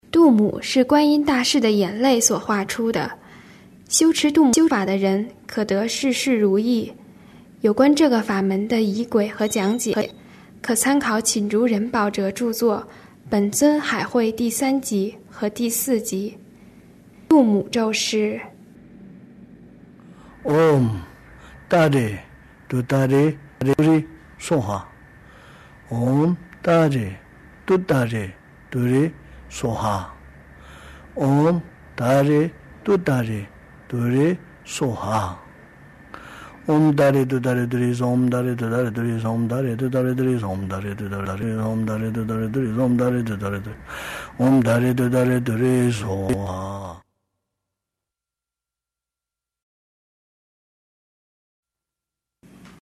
度母咒（藏传念诵
标签: 佛音真言佛教音乐